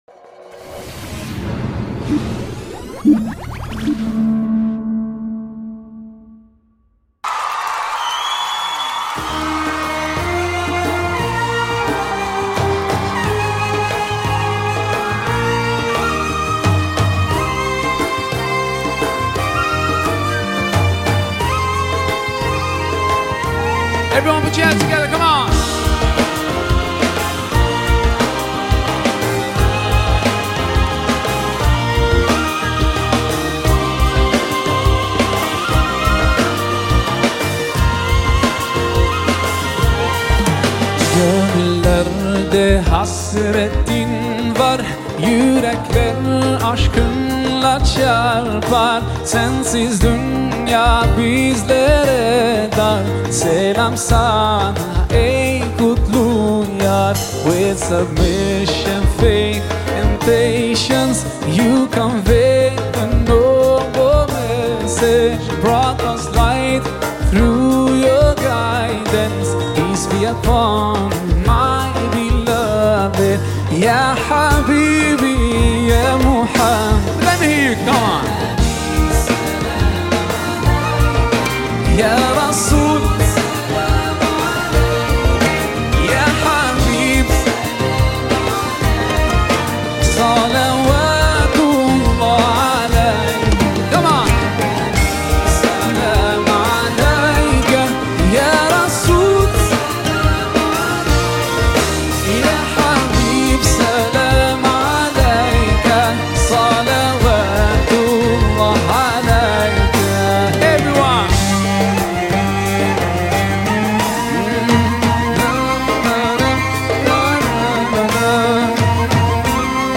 Arabic Islamic Song